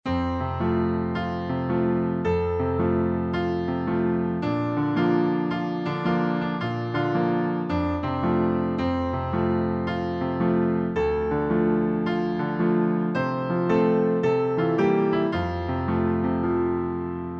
Sheet Music — Piano Solo Download
Piano Solo
Downloadable Instrumental Track